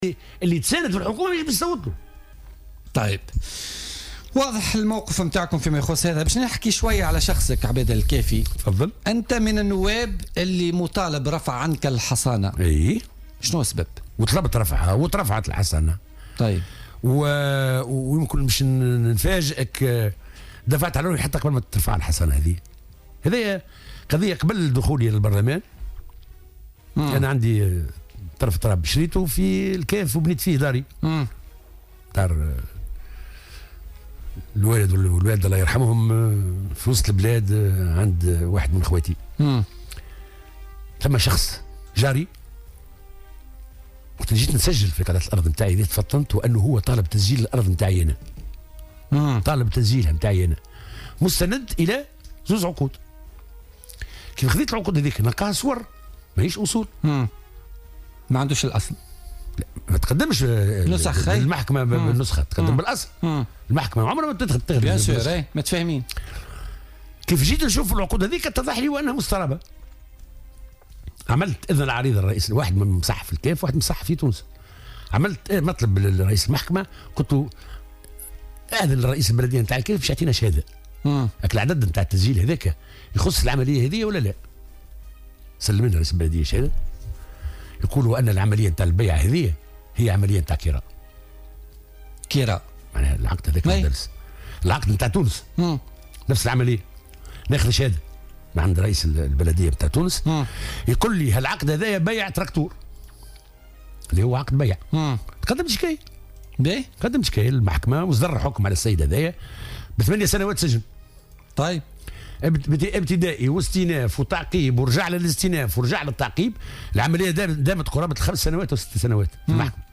وأضاف ضيف "بوليتيكا" أنه طالب برفع الحصانة عنه بسبب تعقد الاجراءات القضائية حول هذا النزاع الذي يعود الى فترة طويلة وقبل دخوله البرلمان.